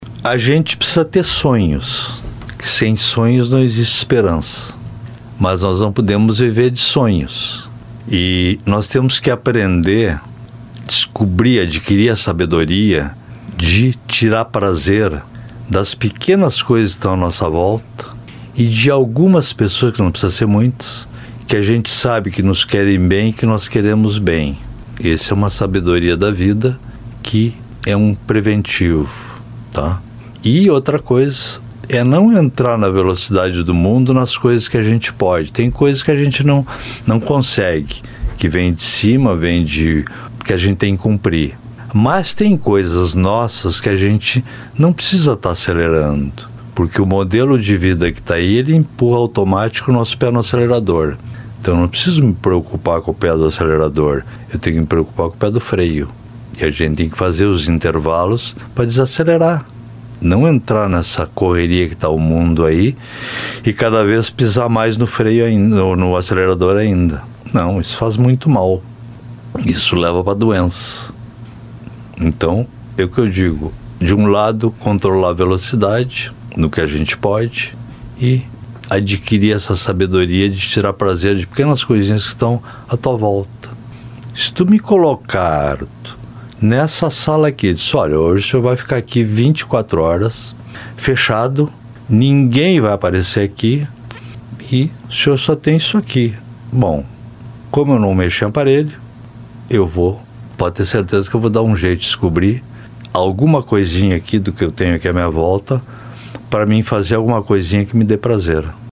Psiquiatria será o tema da entrevista em A Personalidade da Semana